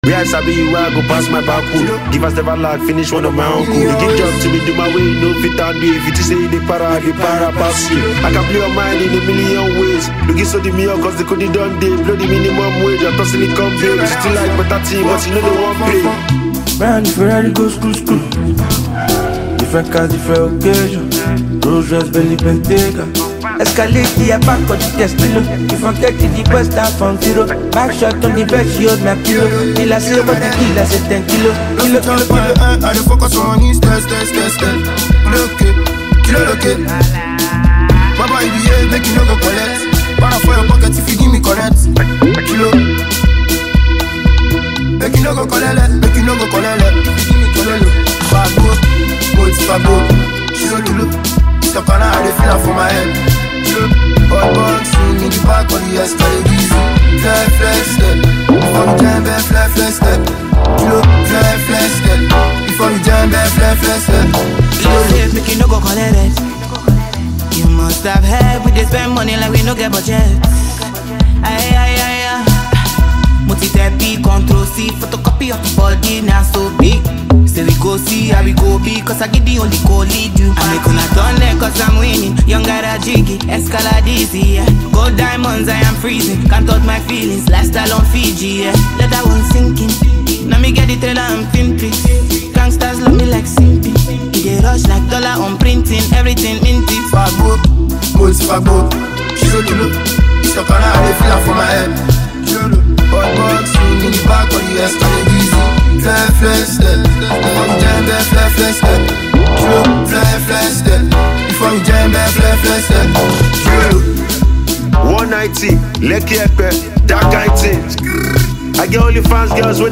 adding charisma and refreshing intensity throughout.